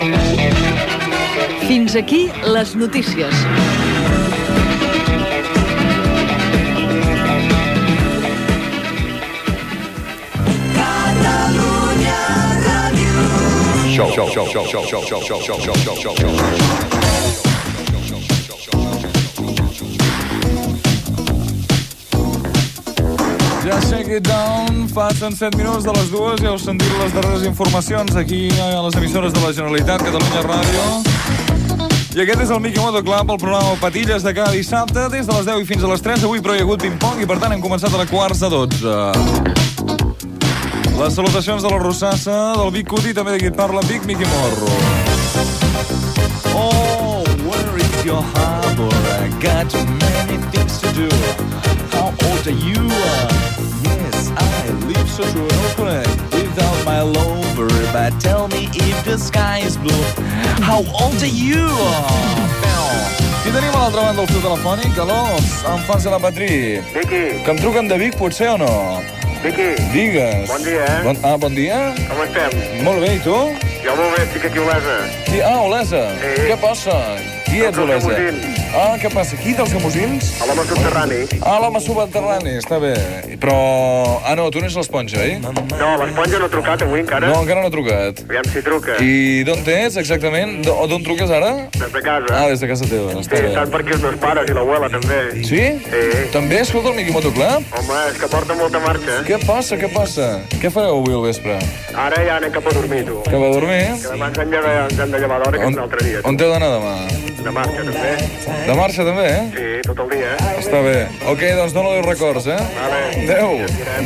Identificació de l'emissora, presentació del programa, equip, trucada telefònica.
Musical
FM